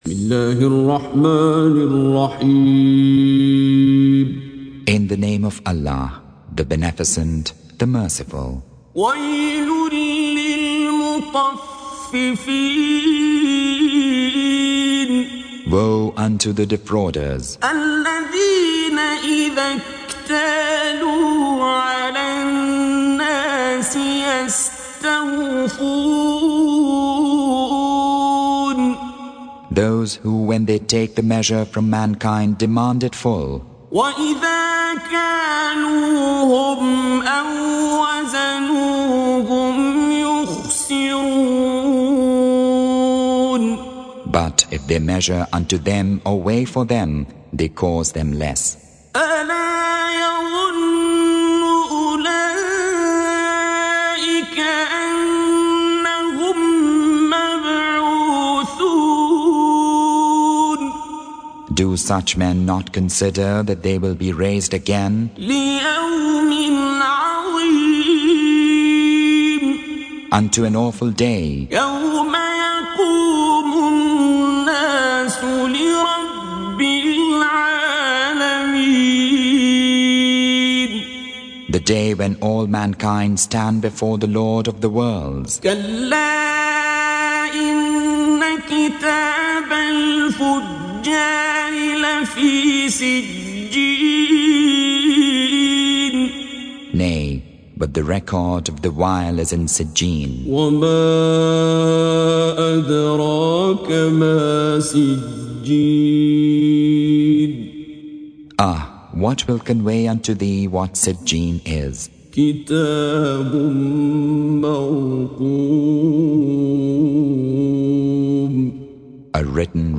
Surah Sequence تتابع السورة Download Surah حمّل السورة Reciting Mutarjamah Translation Audio for 83. Surah Al-Mutaffif�n سورة المطفّفين N.B *Surah Includes Al-Basmalah Reciters Sequents تتابع التلاوات Reciters Repeats تكرار التلاوات